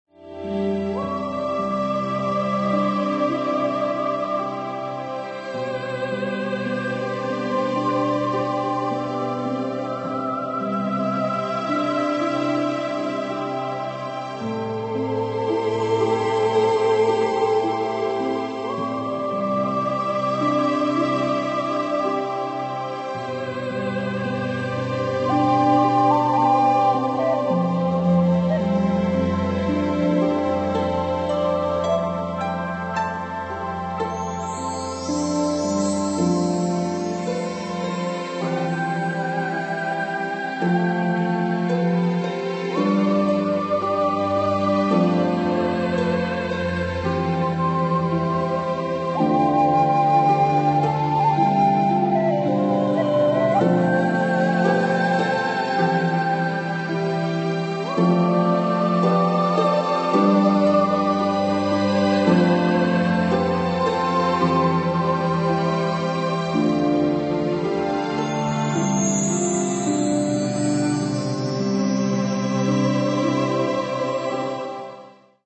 Diese tief beruhigende Musik
Naturgeräusche